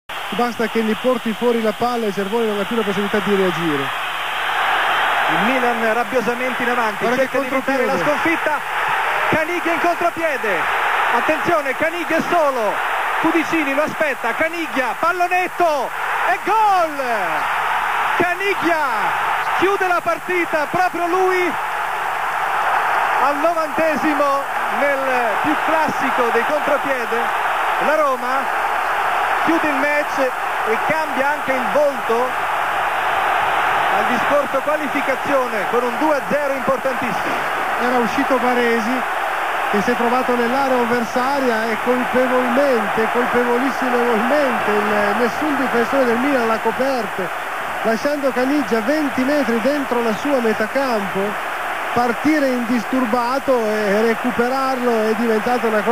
9293gol di caniggia commento di piccinini roma milan coppa italia.wma